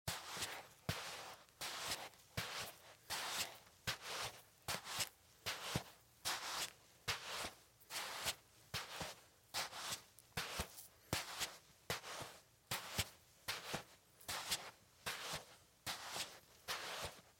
На этой странице собраны разнообразные звуки песка: от шуршания под ногами до шелеста дюн на ветру.